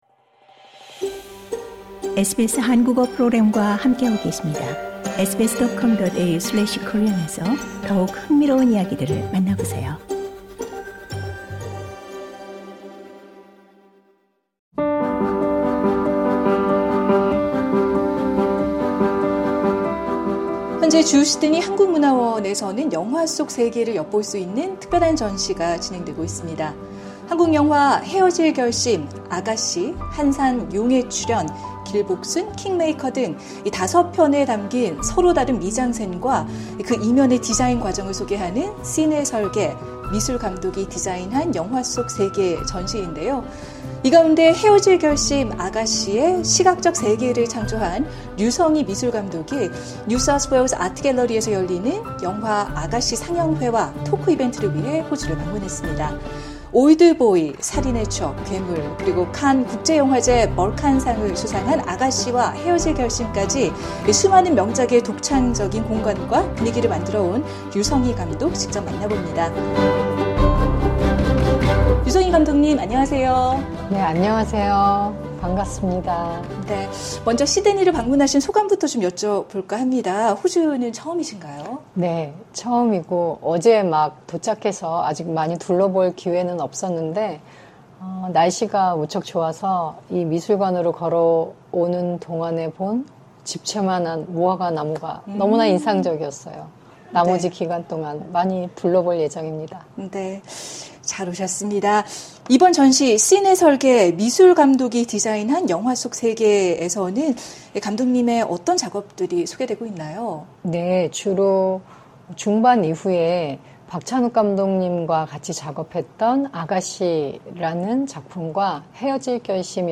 인터뷰: 칸 영화제 수상 류성희 미술 감독 “영화 미술은 고고학적 판타지”